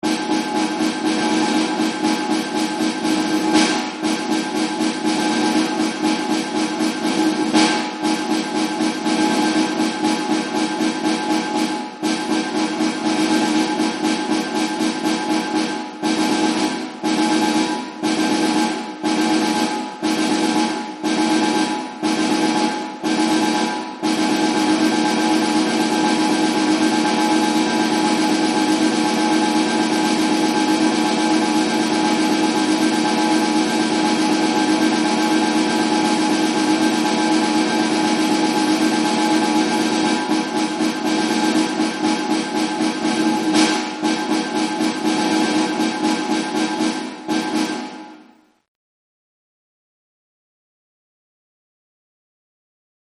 Jeugd Ensemble
Snare drum